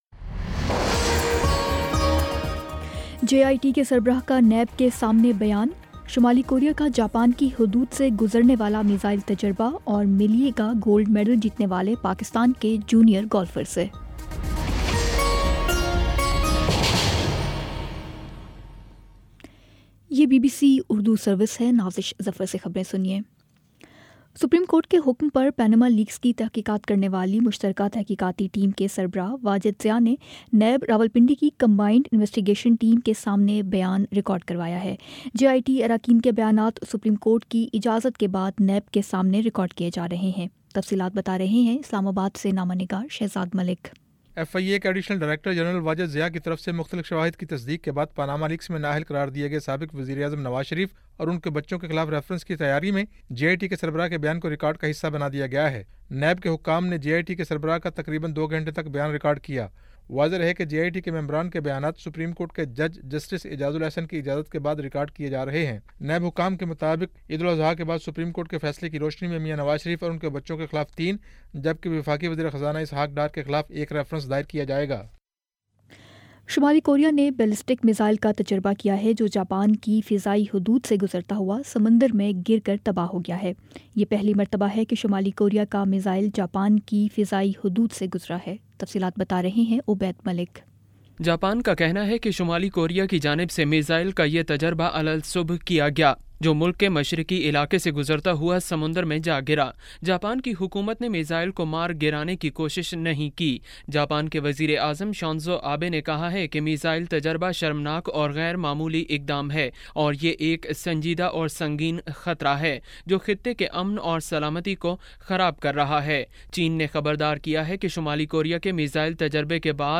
اگست 29 : شام پانچ بجے کا نیوز بُلیٹن